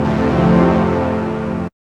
5806L SYNPAD.wav